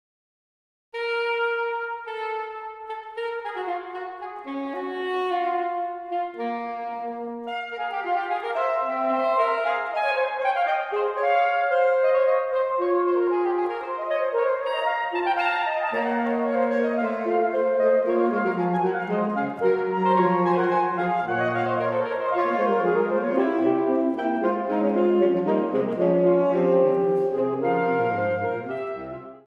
Allegro 2:22